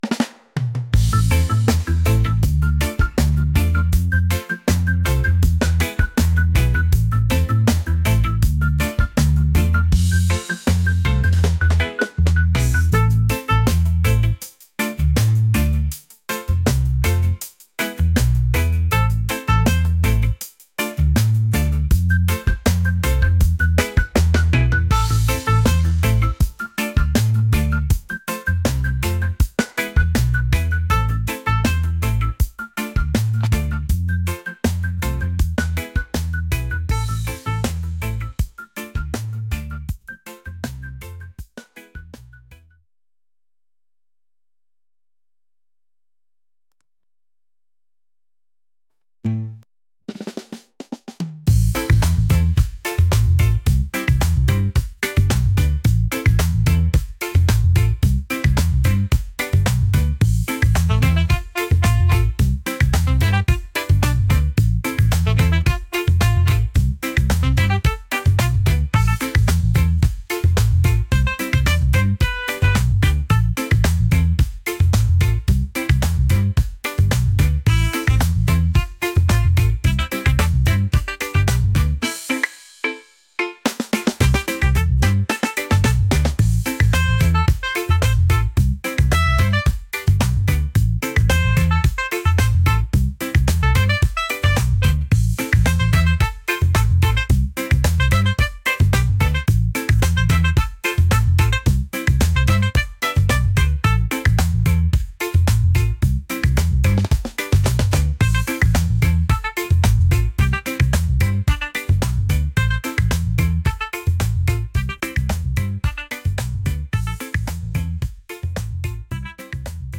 positive | reggae | upbeat